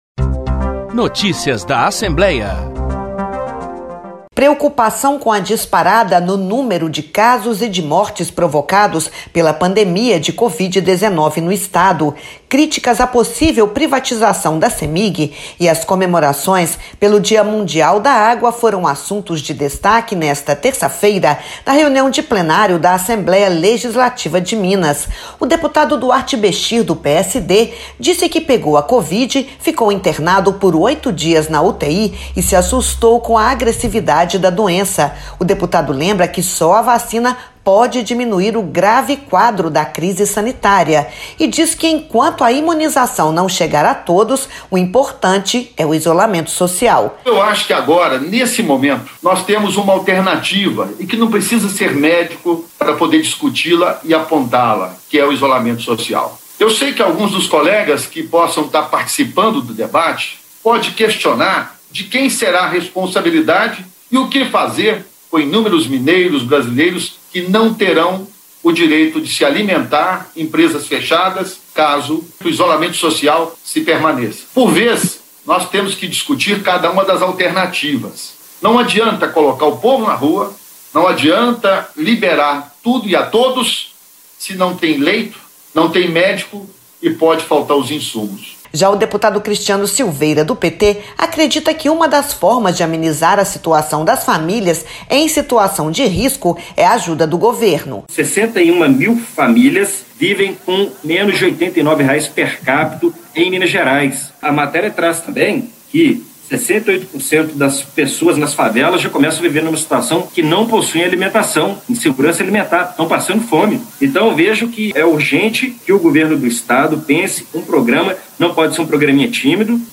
Em Plenário, os parlamentares se posicionaram sobre as situações do cotidiano, entre elas, o aumento expressivo no número de contaminados pelo novo Coronavírus